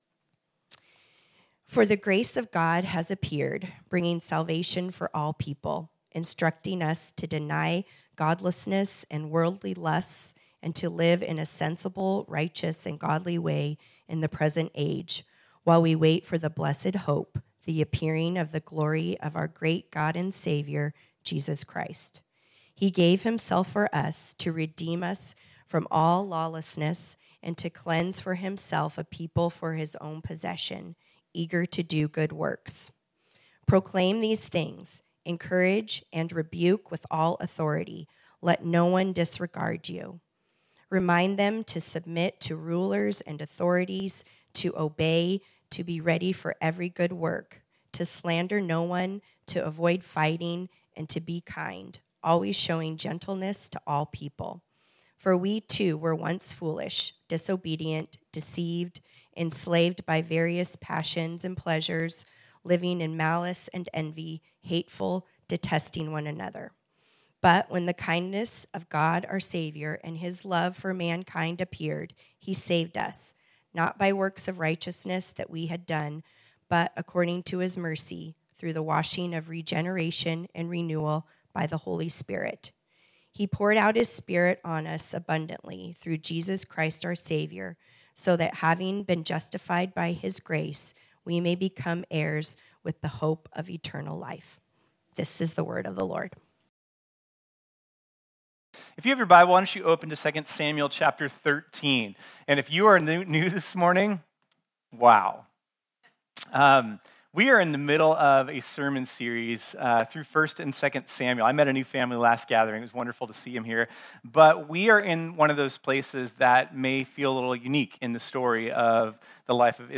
This sermon was originally preached on Sunday, February 26, 2023.